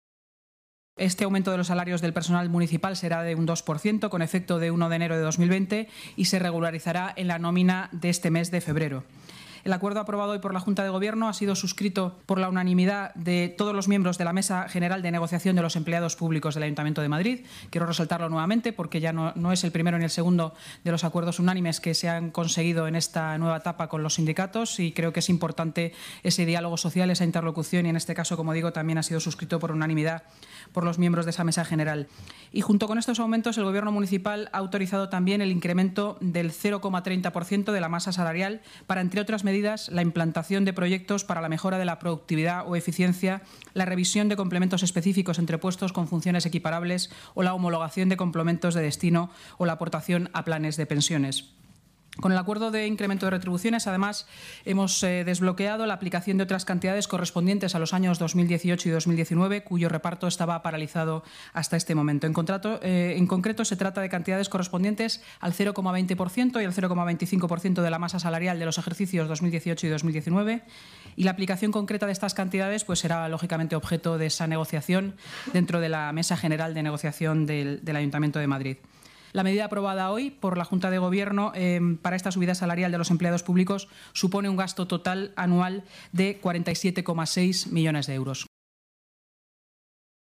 Este aumento de los salarios del personal municipal será de un 2 % con efecto del 1 de enero de 2020 y se regularizará en la nómina de este mes de febrero, tal y como ha informado en rueda de prensa la portavoz municipal Inmaculada Sanz.